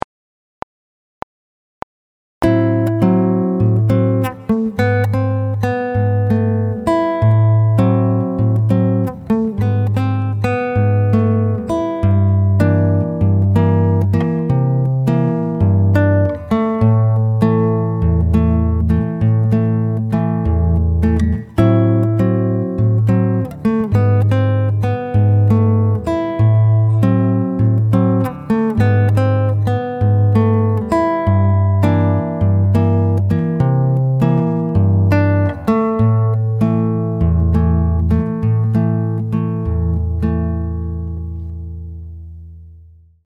Chitarra fingerstyle 16
LUIZ BONFA' Bossa Nova Style